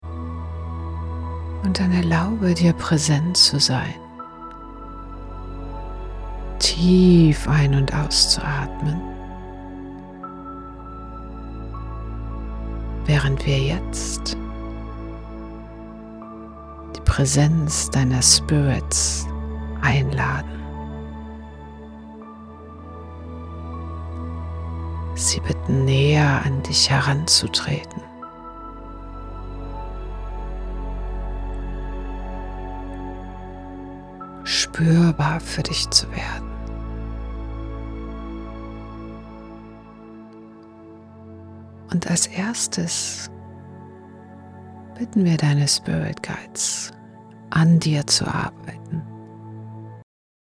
In der Soulful Awakening Membership erwarten dich drei transformative Meditationen, die dich auf deiner Reise zu innerer Klarheit und spirituellem Wachstum begleiten